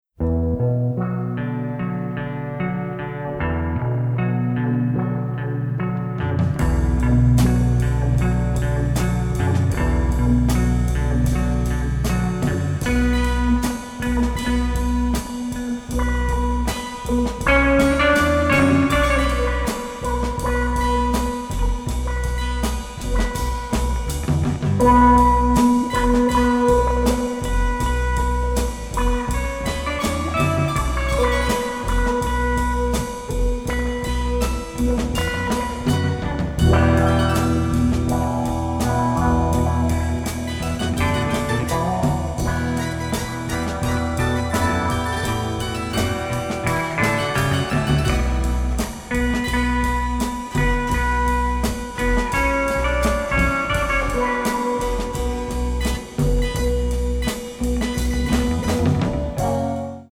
Italian masterpiece of psychedelia!